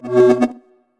countdown-tick-final.wav